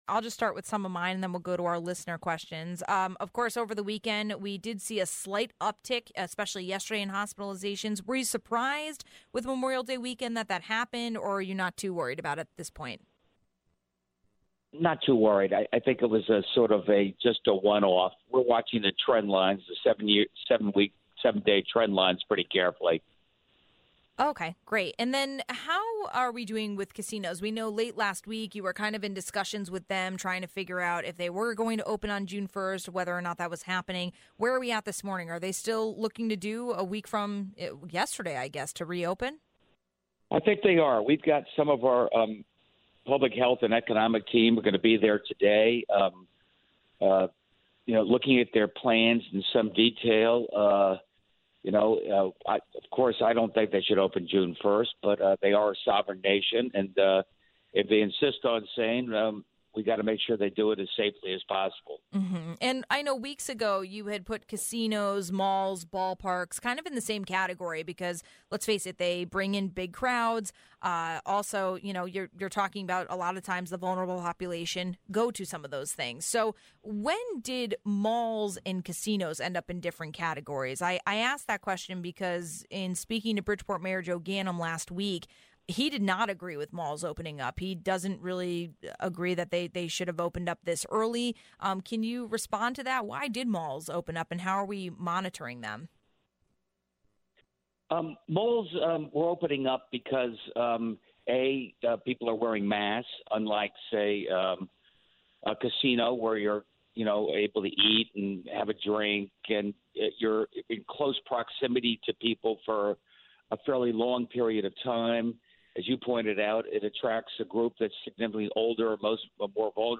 Governor Lamont checks in just after wrapping up Memorial Day weekend. We saw a slight uptick in hospitalizations, find out why he's NOT concerned. But also, the governor answers your questions about covid-19 and the state.